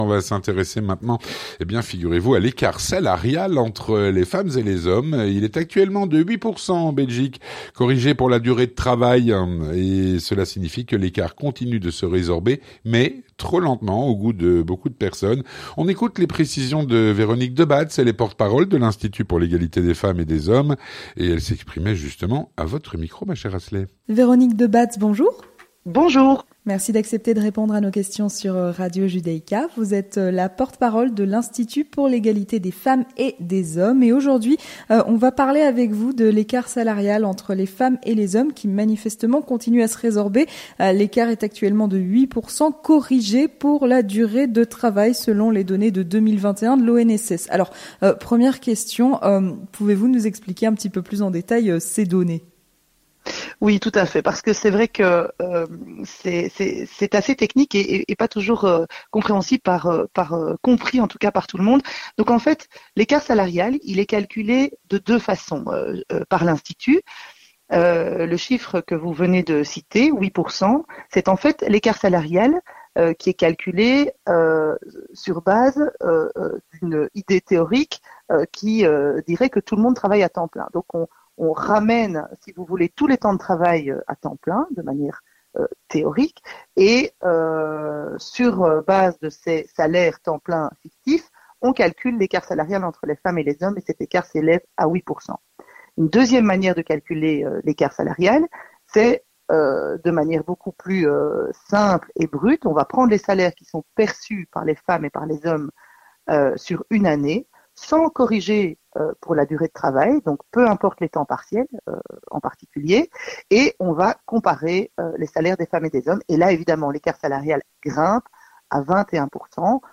Entretien du 18h - L'écart salarial femmes-hommes en Belgique